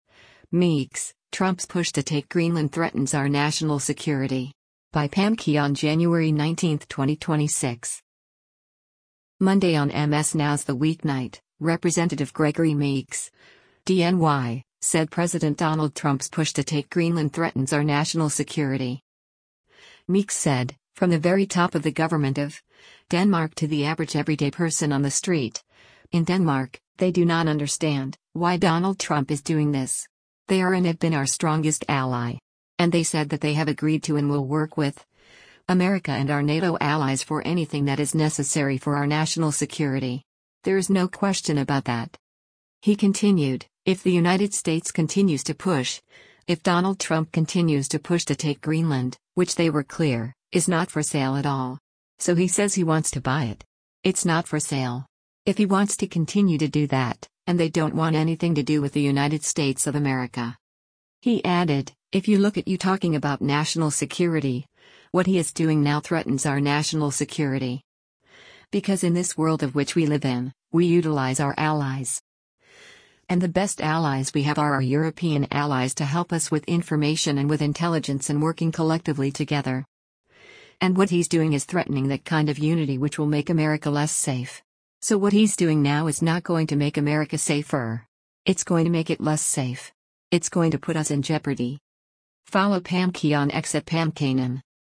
Monday on MS NOW’s “The Weeknight,” Rep. Gregory Meeks (D-NY) said President Donald Trump’s push to take Greenland “threatens our national security.”